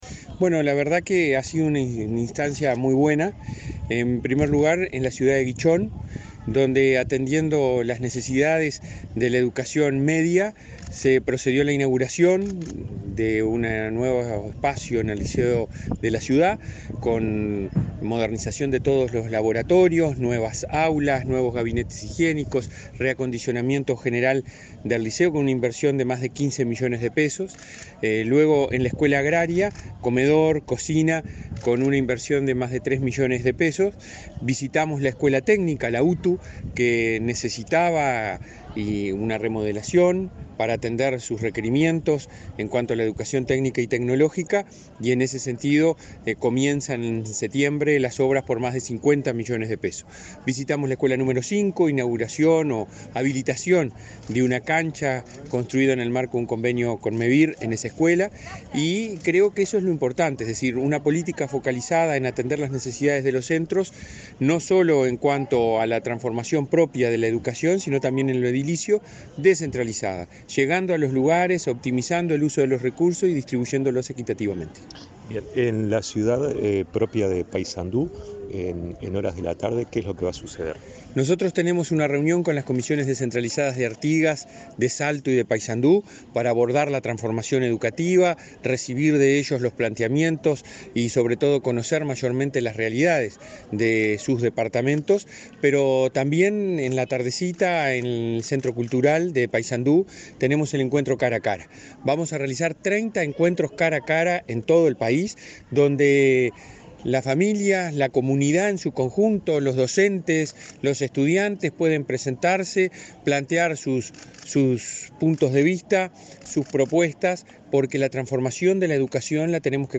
Entrevista al presidente de ANEP, Robert Silva